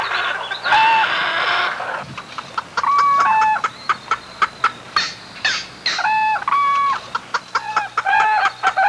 Aviary Sound Effect
aviary-1.mp3